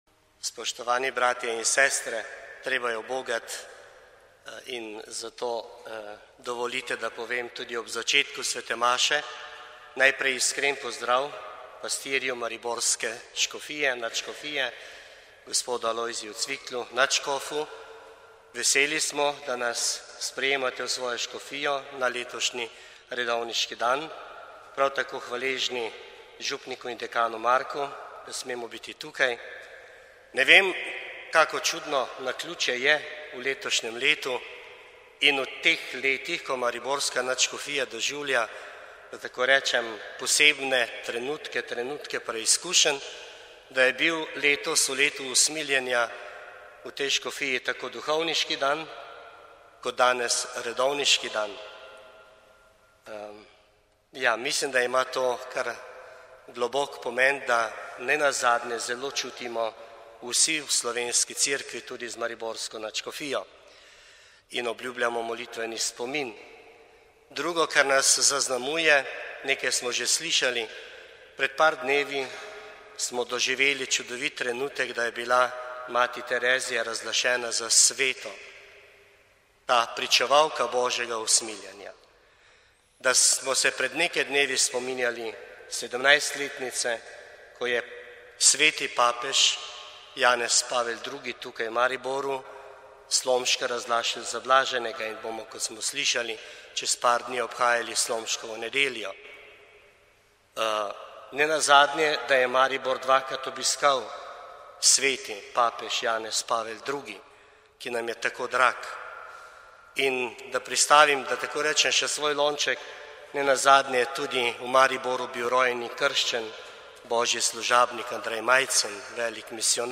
V živo z 42. redovniškega dneva v Mariboru